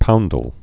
(poundl)